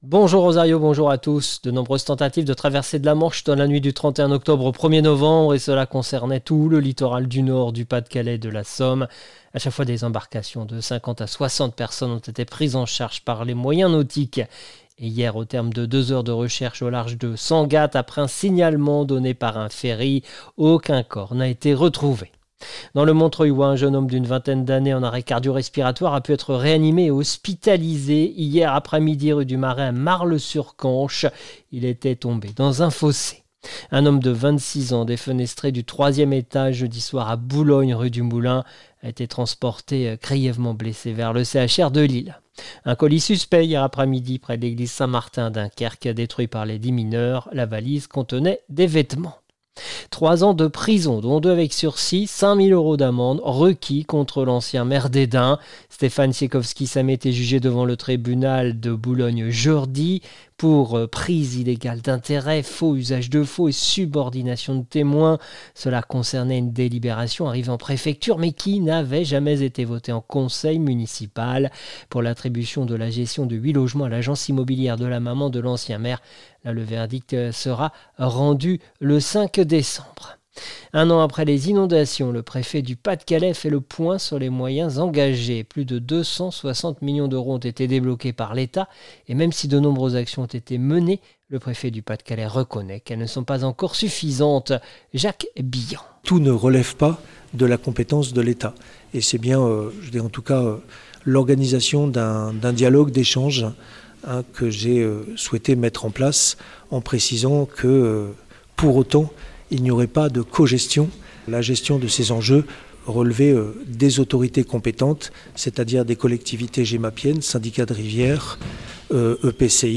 Le journal du samedi 2 novembre 2024